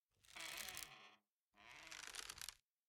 02_孤儿院走廊_秋千声音.ogg